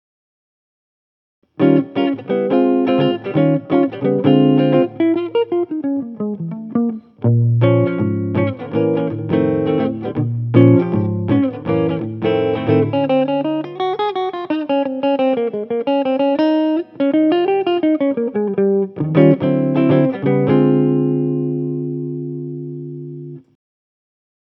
Clean Jazz sample Smooth Jazz. Channel 1
Fat jazz box.mp3